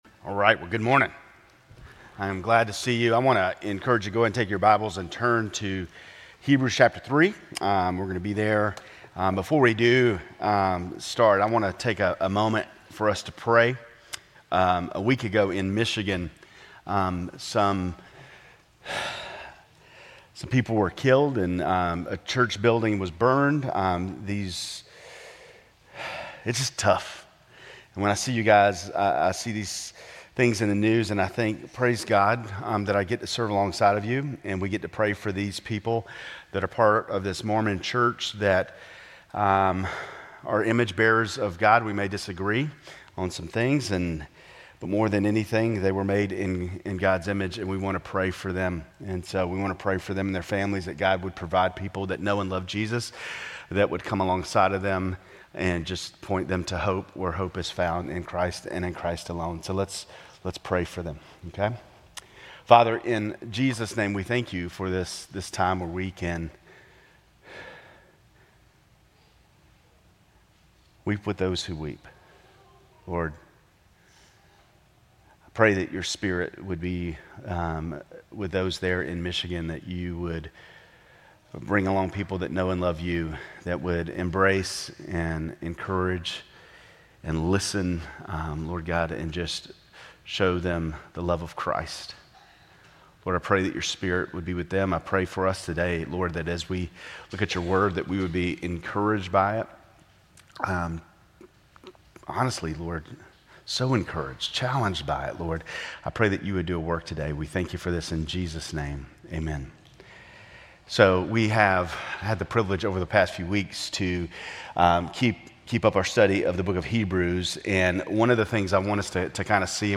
Grace Community Church Lindale Campus Sermons 10_5 Lindale Campus Oct 06 2025 | 00:33:29 1x 00:00 / 00:33:29 Subscribe Share RSS Feed Share Link Embed